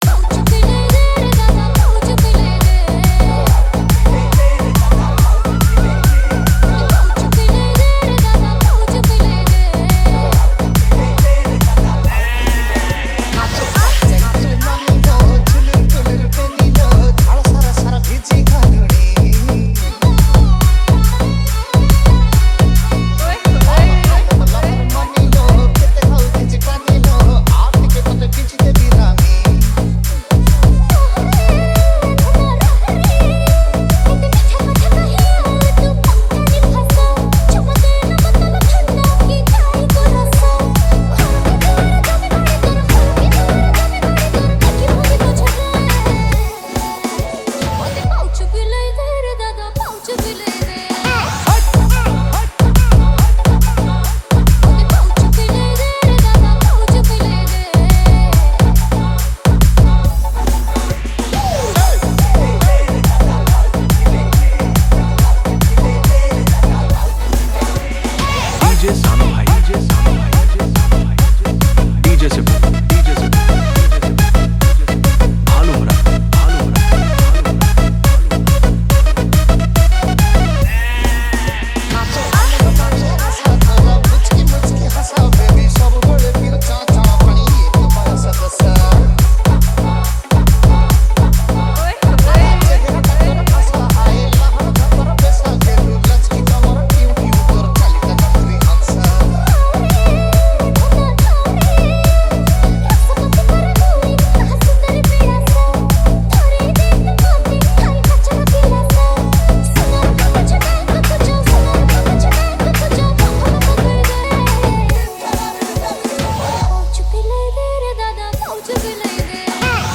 Category:  New Odia Dj Song 2025